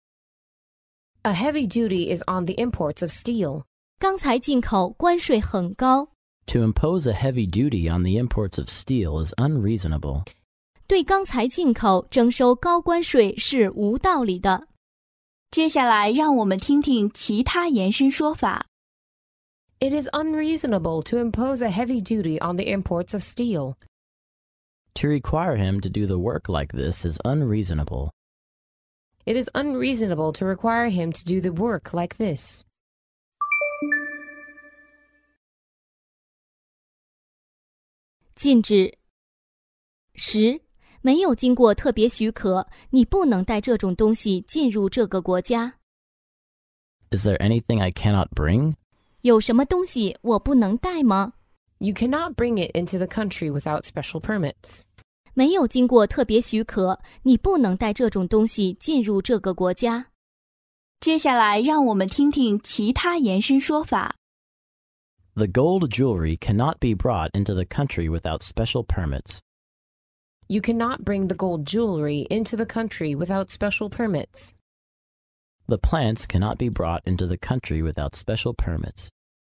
在线英语听力室法律英语就该这么说 第138期:对钢材进口征收高关税是无道理的的听力文件下载,《法律英语就该这么说》栏目收录各种特定情境中的常用法律英语。真人发音的朗读版帮助网友熟读熟记，在工作中举一反三，游刃有余。